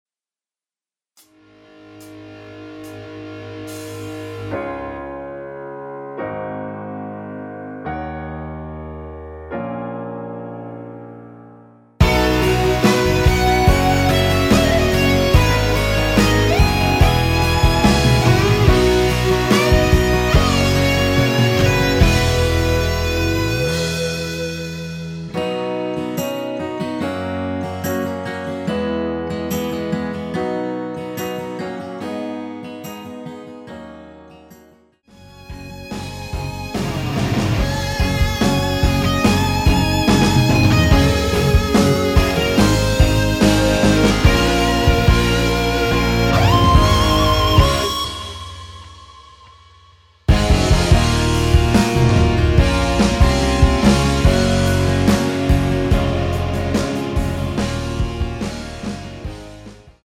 전주 없는 곡이라 전주1마디 만들어 놓았으며
피아노시작 되는 부분 부터 노래 들어가시면 되겠습니다.(미리듣기참조)
◈ 곡명 옆 (-1)은 반음 내림, (+1)은 반음 올림 입니다.
앞부분30초, 뒷부분30초씩 편집해서 올려 드리고 있습니다.
중간에 음이 끈어지고 다시 나오는 이유는